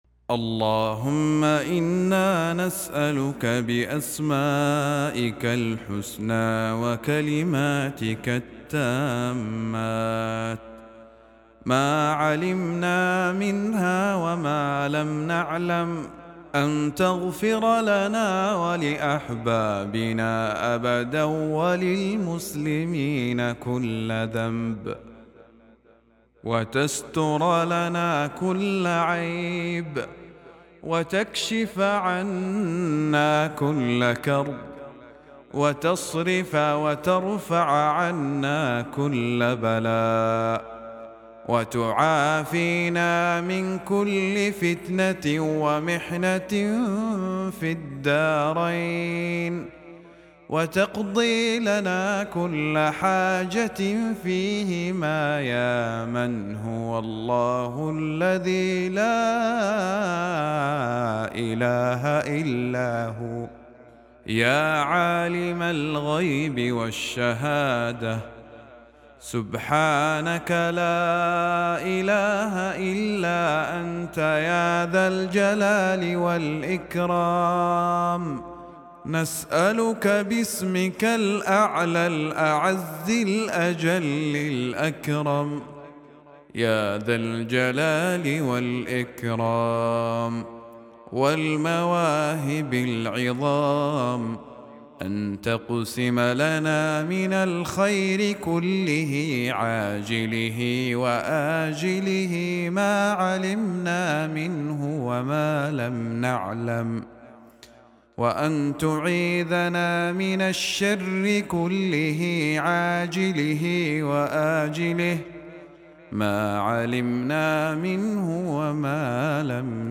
دعاء جامع يتوسل فيه الداعي إلى الله بأسمائه الحسنى وكلماته التامات، طالباً الستر وقضاء الحوائج ودفع البلاء وجلب الخير. يعبر النص عن حالة من الافتقار والأنس بالله واللجوء إليه في كل الأمور.